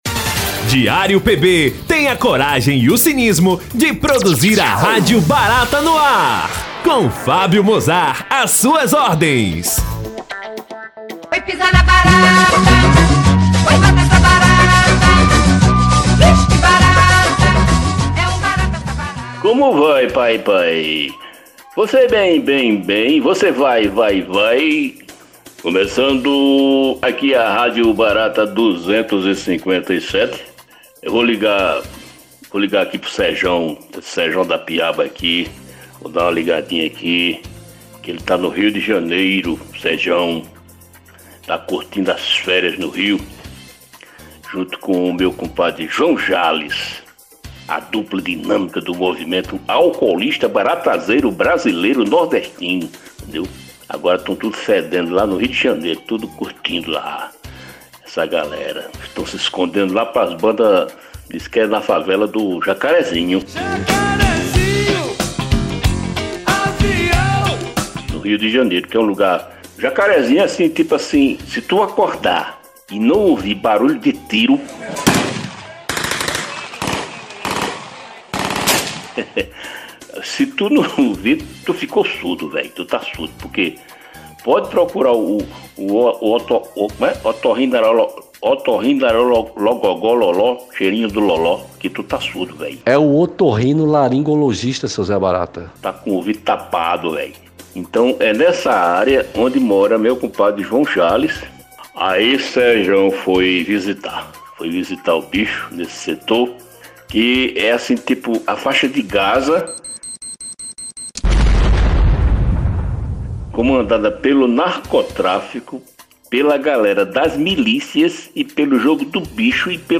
O objetivo do programa é abordar assuntos do cotidiano, comentar de maneira bem humorada, os acontecimentos da semana, de forma irreverente e leve, sempre com boas piadas.